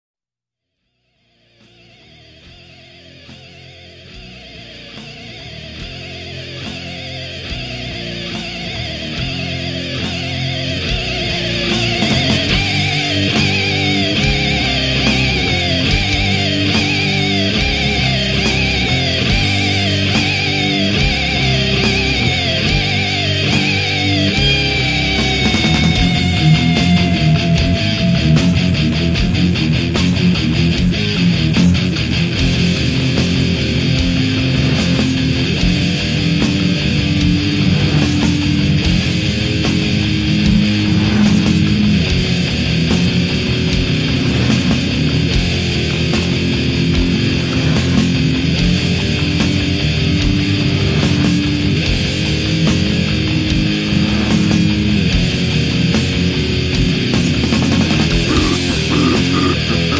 English rock band
vocals
guitars, guitar effects, flute
bass, bass effects
drums, percussion